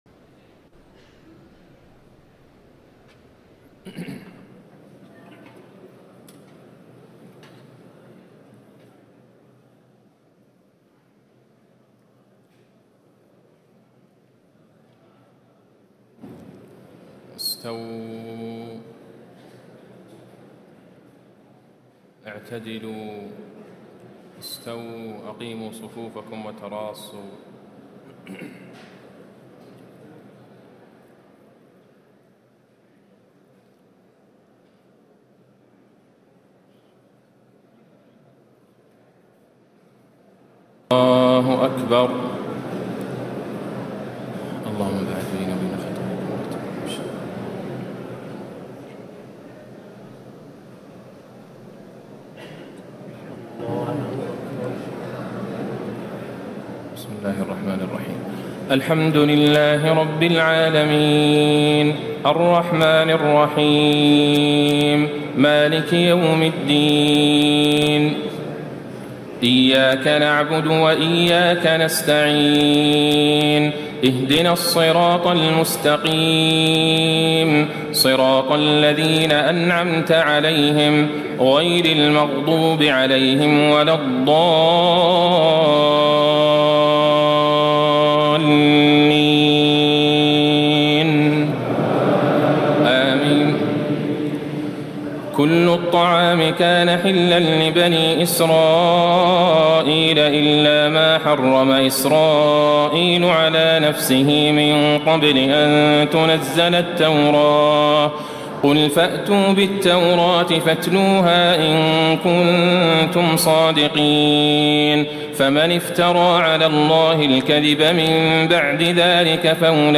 تهجد ليلة 24 رمضان 1436هـ من سورة آل عمران (93-185) Tahajjud 24 st night Ramadan 1436H from Surah Aal-i-Imraan > تراويح الحرم النبوي عام 1436 🕌 > التراويح - تلاوات الحرمين